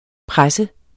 Udtale [ ˈpʁasə ]